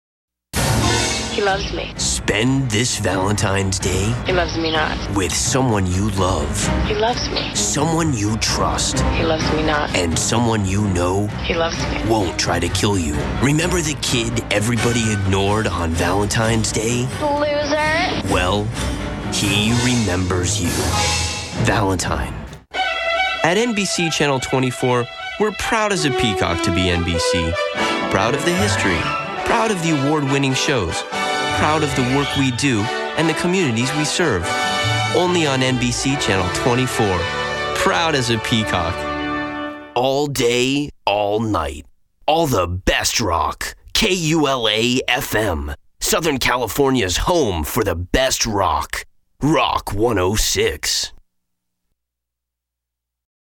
His range of vocal ability spans from young to old and is capable of cartoon voices to movie previews and promos.
Commercials demo 2:36 Promos demo 0:53